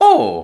Super Mario Ohhh